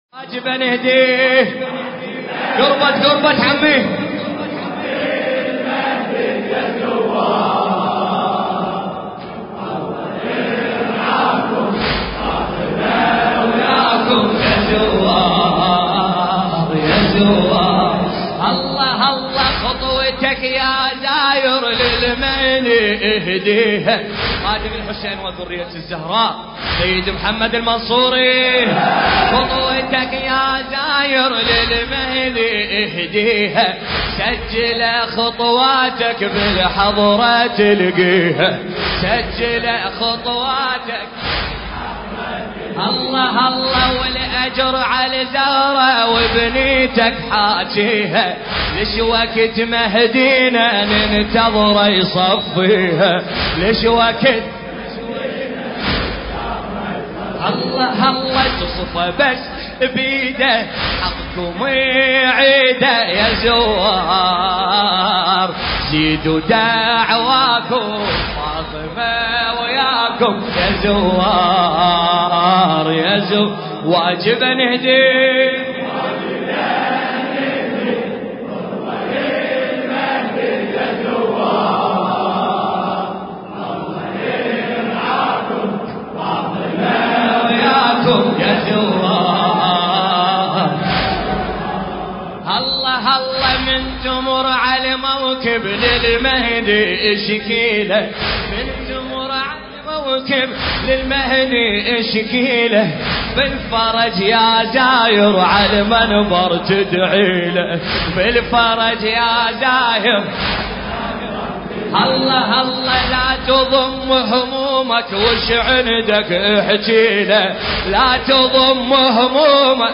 المراثي
الحجم: 2.63 MB الشاعر: سيد محمد المنصوري المكان: حسينية آل ياسين هيئة احباب الامام الحسن عليه السلام الكوفة العلوية – حي ميسان